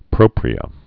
(prōprē-ə)